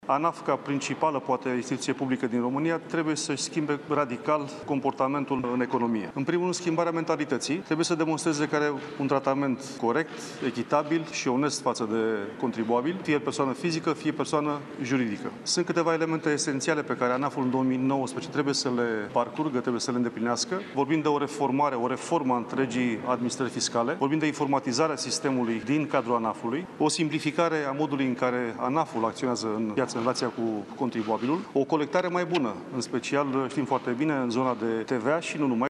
Ministrul de Finanţe, Eugen Teodorovici, a explicat, astăzi, că vrea o reformă în instituţie şi i-a propus premierului un nou şef care să o aplice: